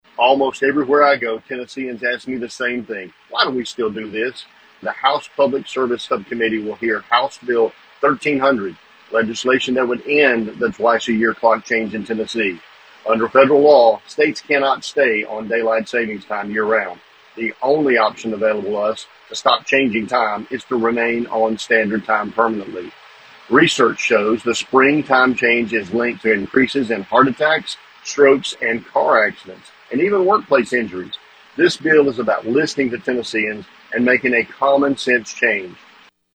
Representative Todd explained his proposal on an Instagram post.(AUDIO)